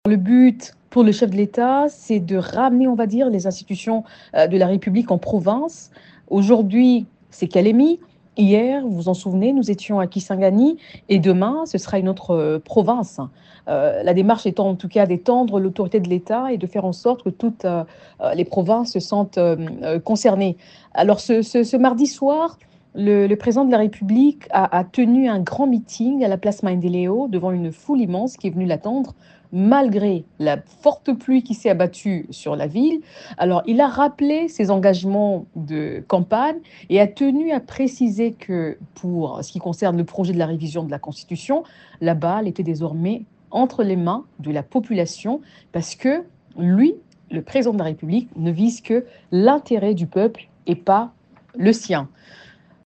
A Radio Okapi, Tina Salama, porte-parole du président de la République, a expliqué les raisons de la tenue de la Conférence des gouverneurs et du Conseil des ministres à partir de la capitale provinciale du Tanganyika :